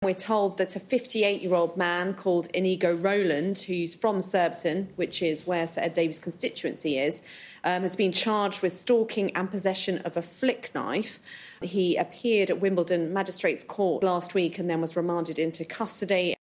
Political correspondent